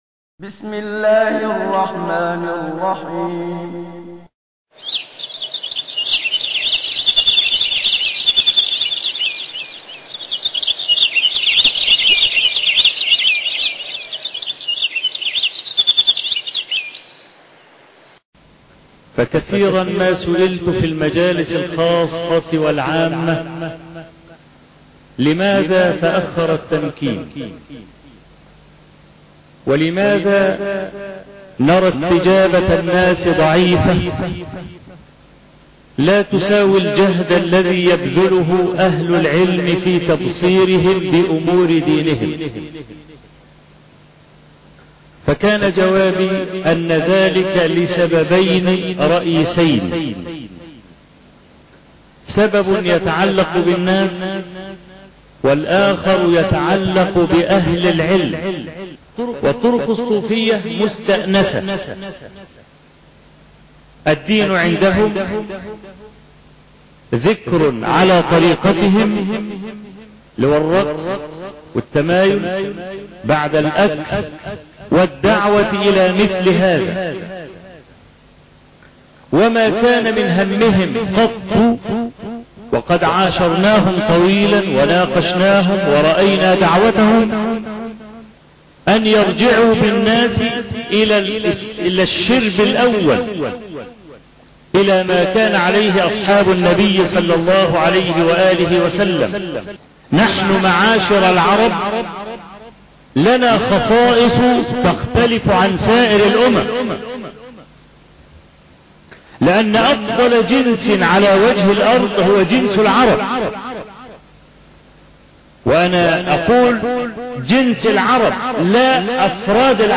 الخطبة التاسعة (سلم الصعود ) - الشيخ أبو إسحاق الحويني